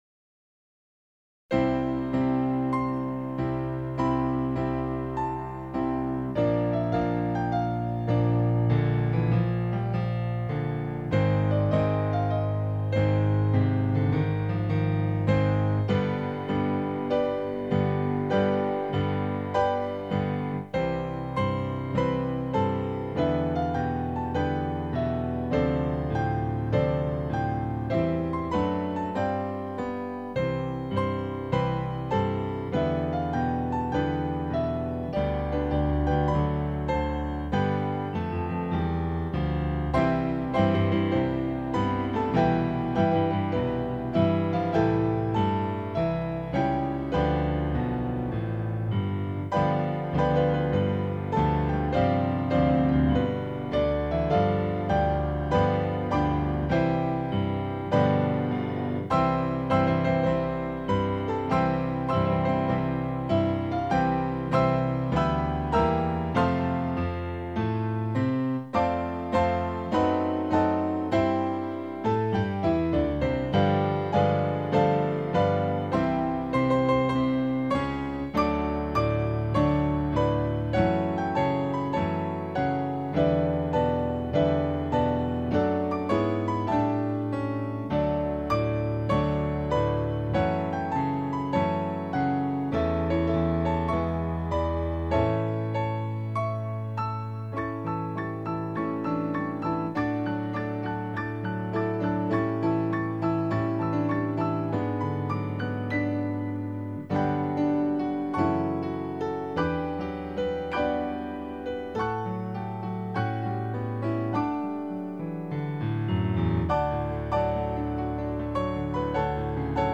Voicing/Instrumentation: Piano Duet/Piano Ensemble